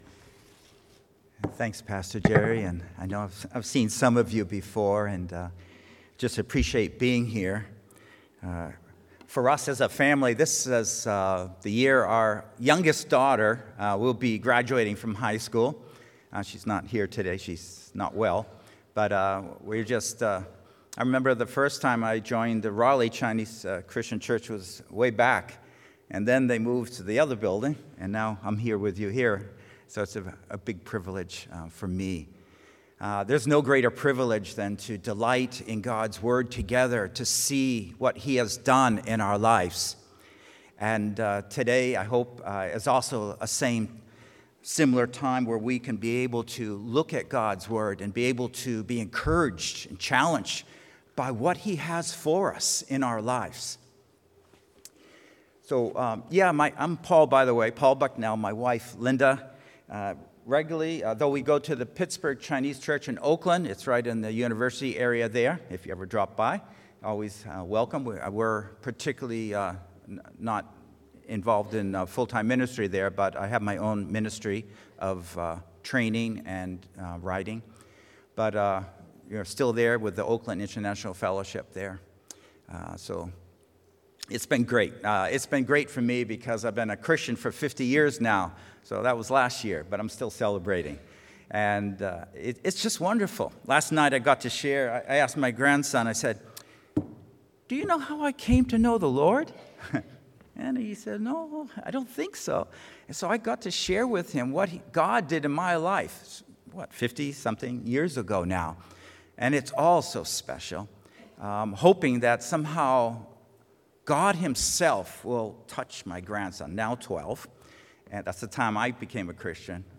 The Spirit-dependent Life – Raleigh Chinese Christian Church